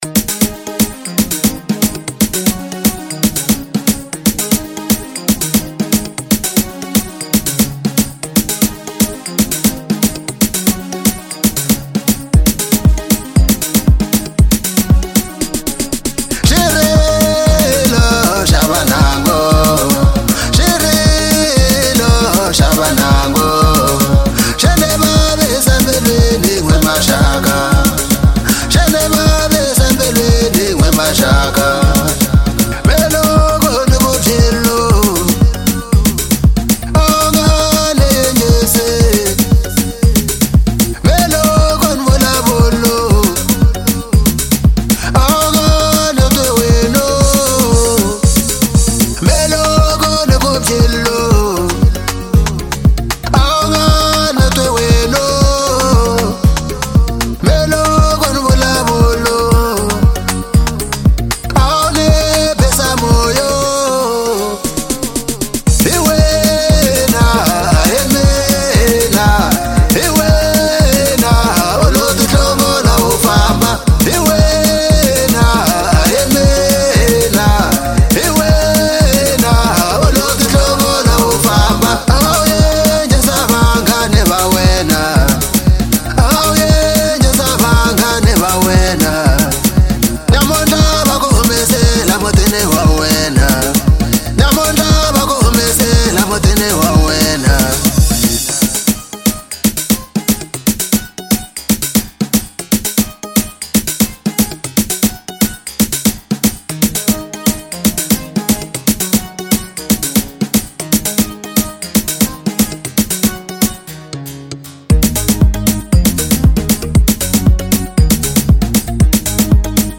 04:58 Genre : African Disco Size